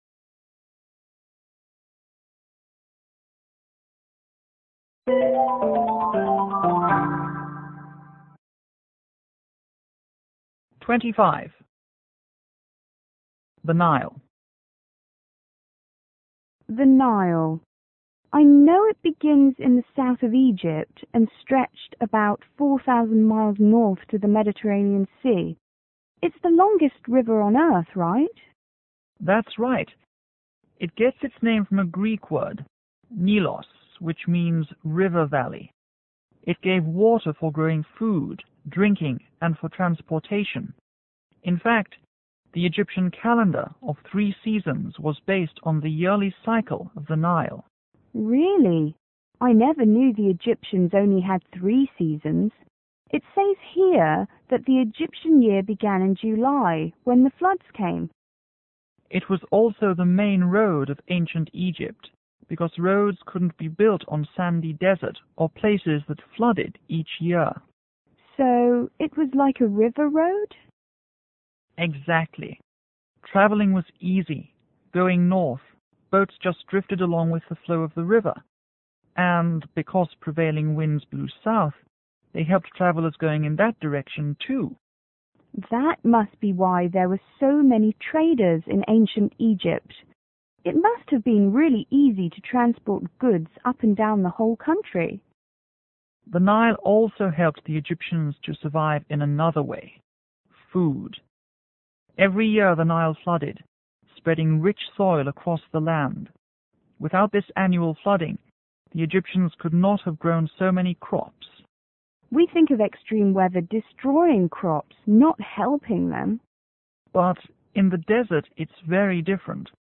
T: Traveller    G:Tour guide